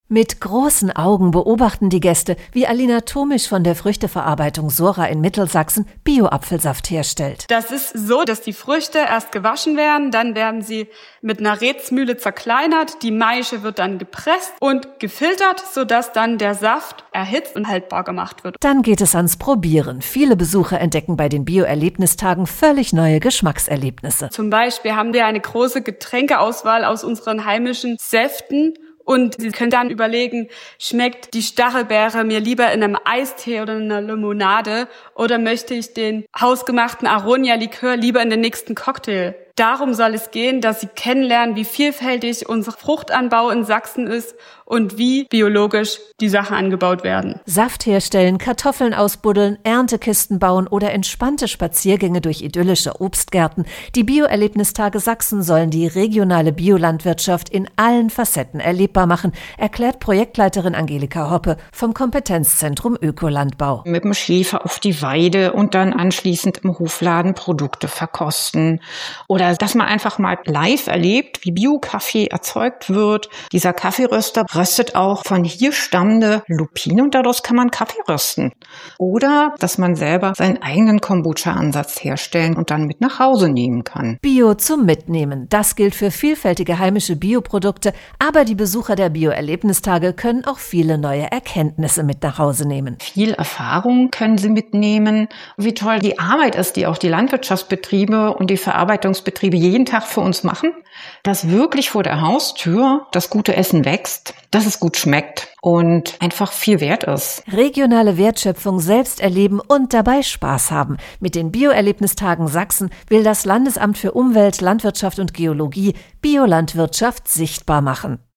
Radiobeitrag Bio-Erlebnistage 2024